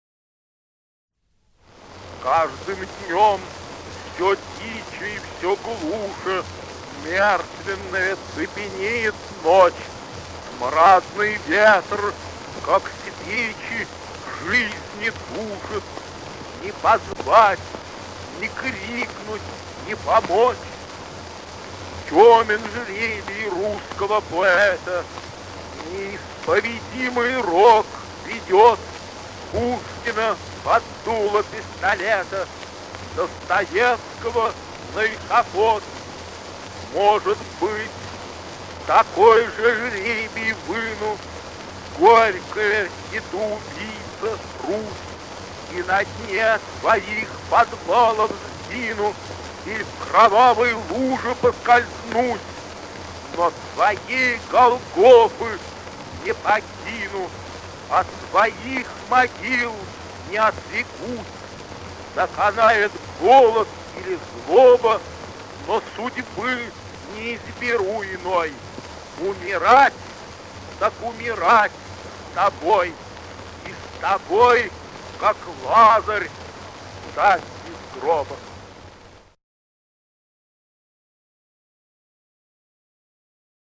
5. «Максимилиан Волошин – На дне преисподней (читает автор)» /
Voloshin-Na-dne-preispodney-chitaet-avtor-stih-club-ru.mp3